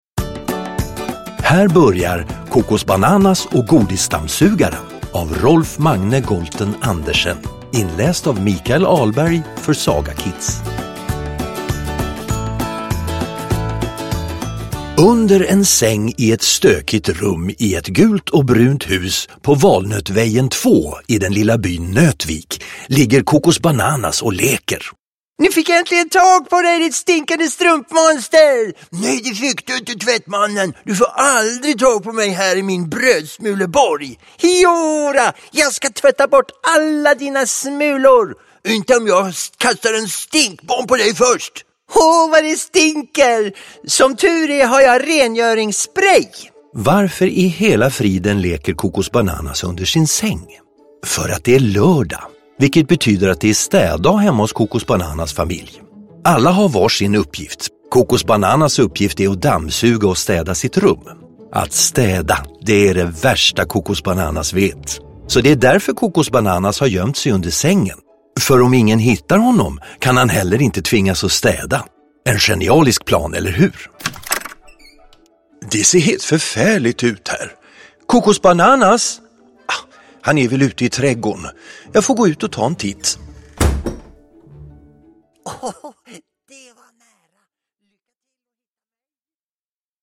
Kokosbananas och godisdammsugaren – Ljudbok
Med härliga ljudeffekter och musik bjuder Kokosbananas på underhållning för hela familjen!